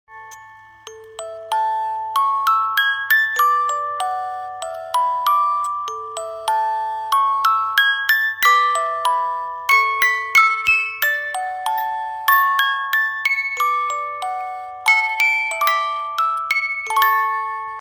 Romántico